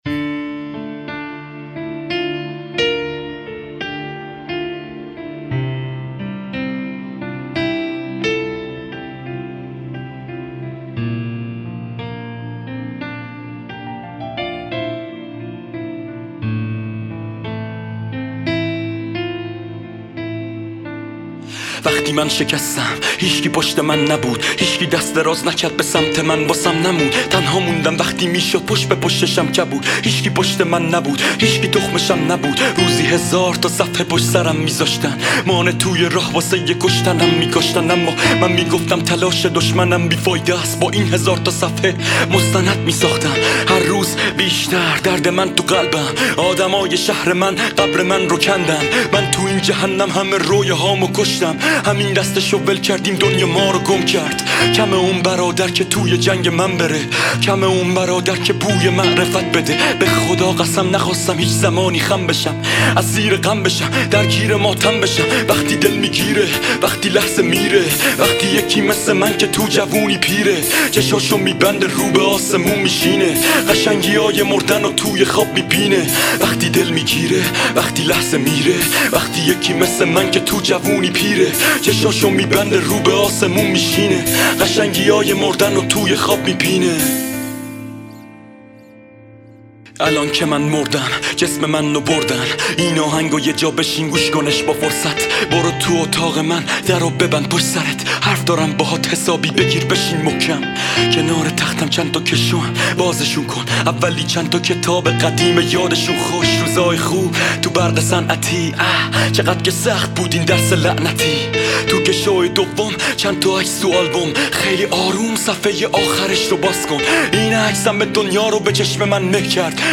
آهنگ جدید عاشقانه و جذاب